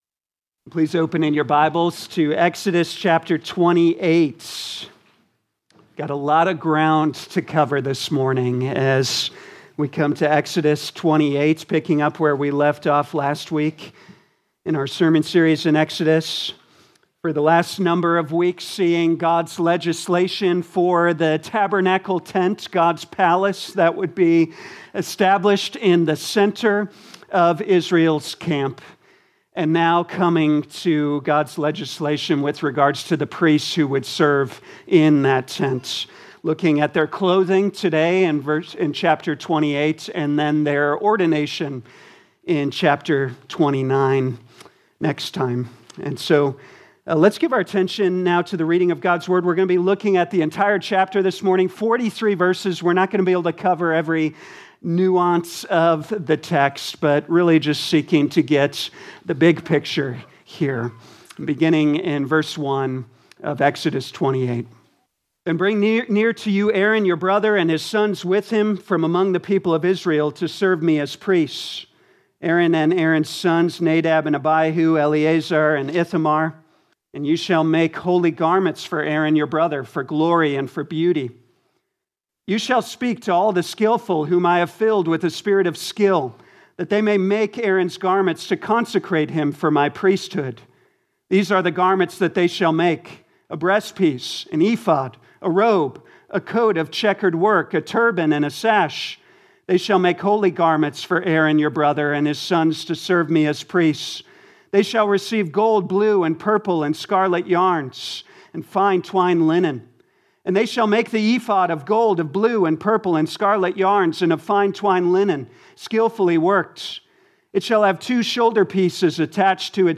2026 Exodus Morning Service Download